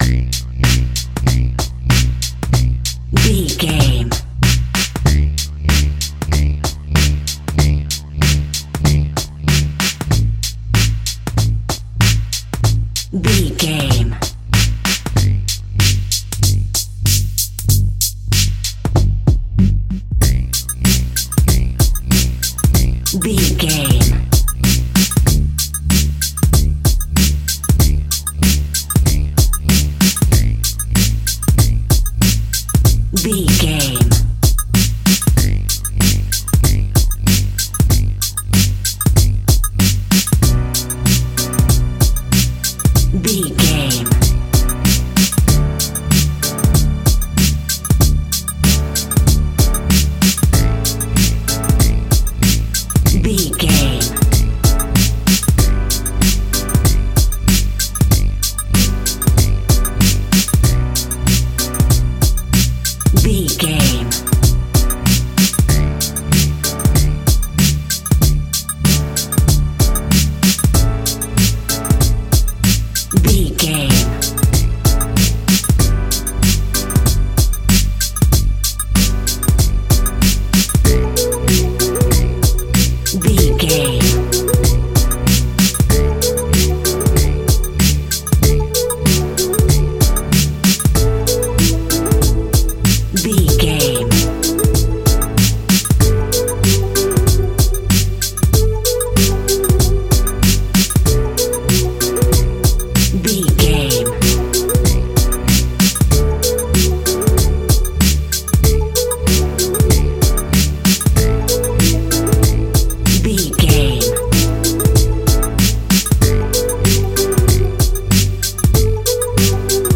Aeolian/Minor
hip hop instrumentals
hip hop beats
downtempo
synth lead
synth bass
synth drums
turntables